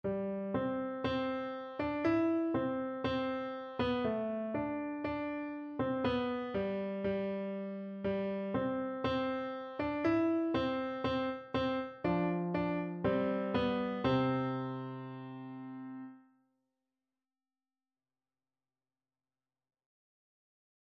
Cheerfully = c. 120
2/2 (View more 2/2 Music)
Beginners Level: Recommended for Beginners
Piano  (View more Beginners Piano Music)